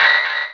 Cri d'Anorith dans Pokémon Rubis et Saphir.